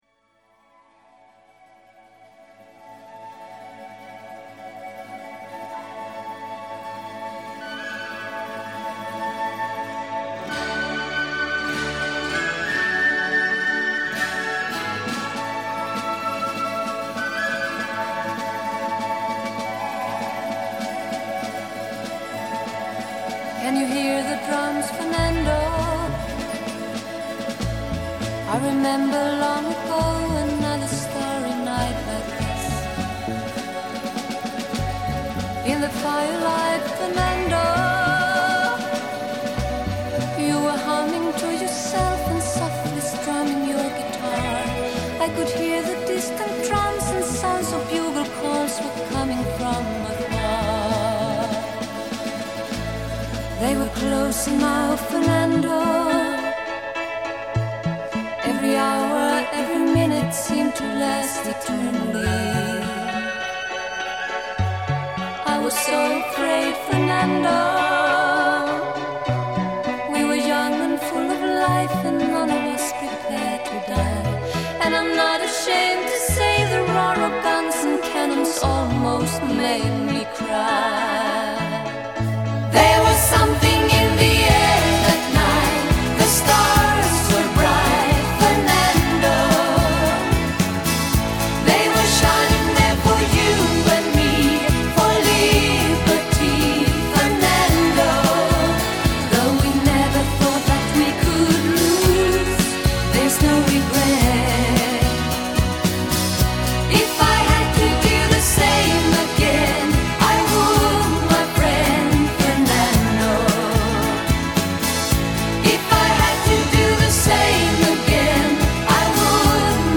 Главная » Популярная музыка